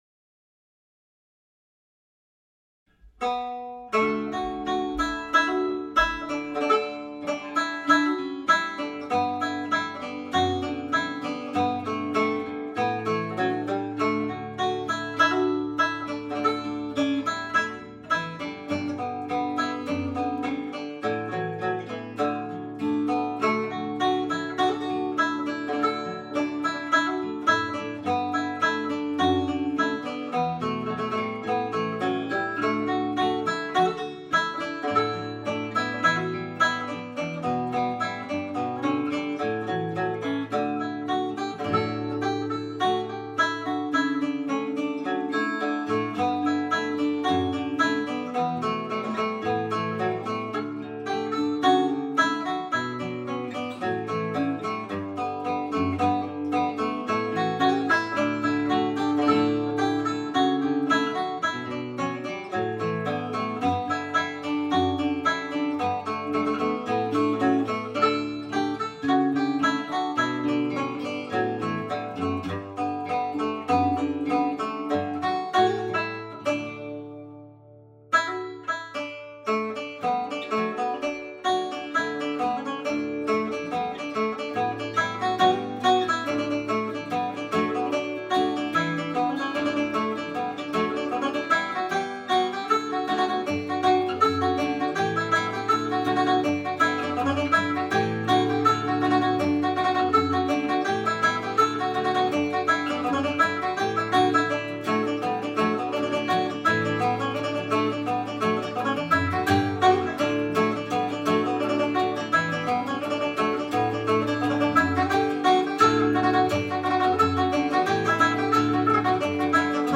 Belfast Trad Advanced class (Mid-Term Break) practice tune
The Air Tune is played as a slow reel in the key of D major.
guitar